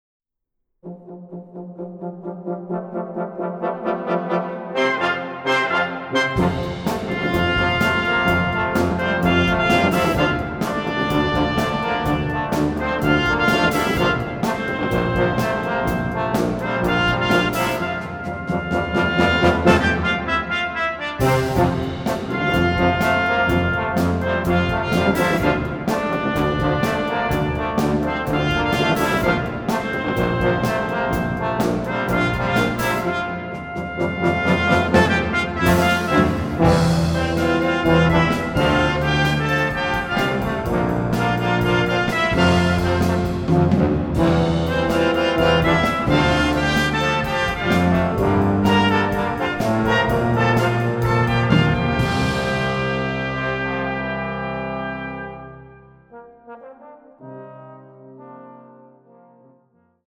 Music for trombone choir and brass ensemble
Brass ensemble